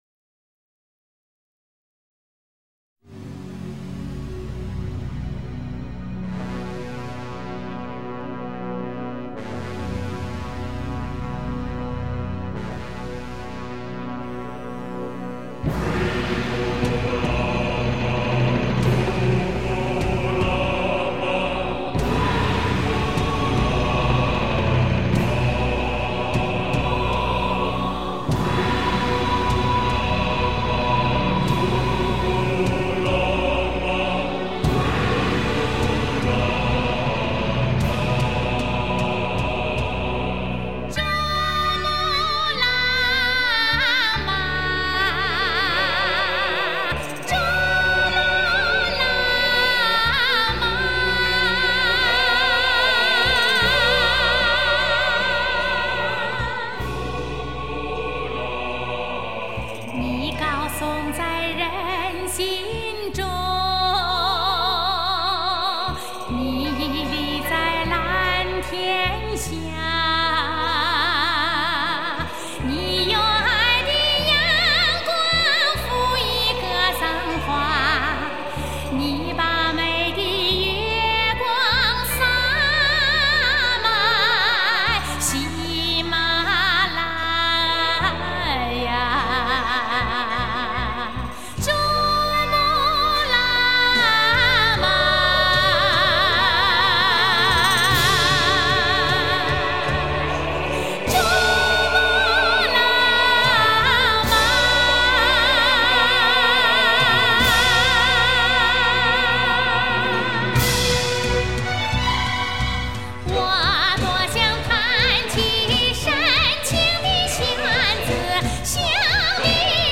音乐风格流行